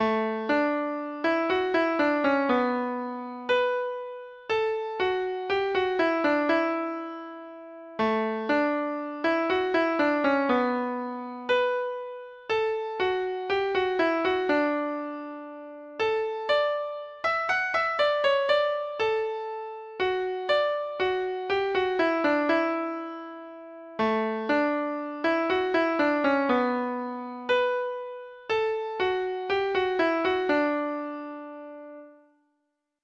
Folk Songs from 'Digital Tradition' Letter A A War Song
Treble Clef Instrument  (View more Intermediate Treble Clef Instrument Music)
Traditional (View more Traditional Treble Clef Instrument Music)